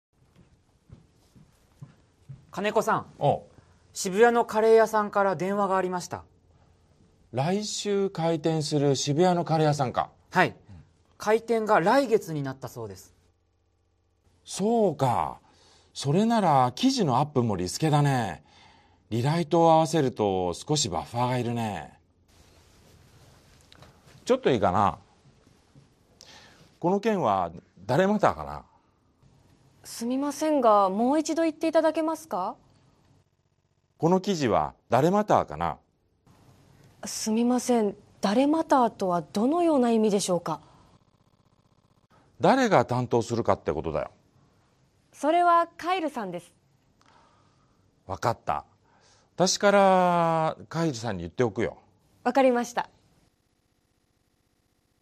Role-play Setup
skit02.mp3